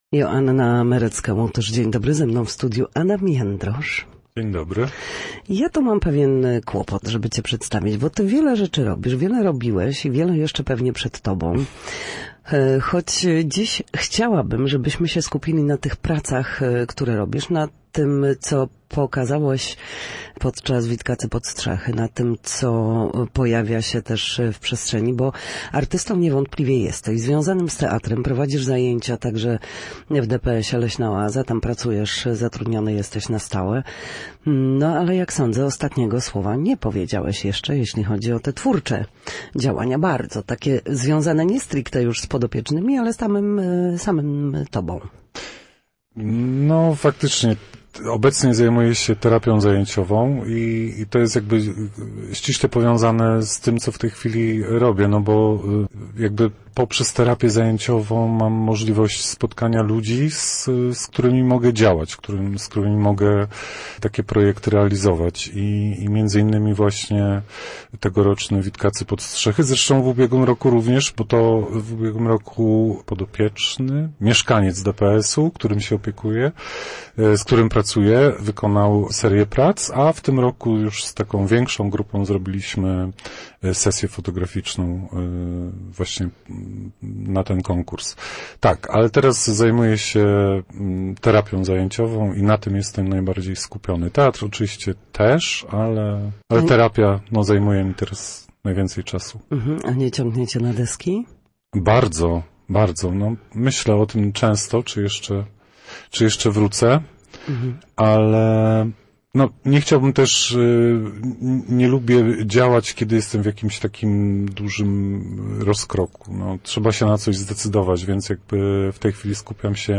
Gościem Studia Słupsk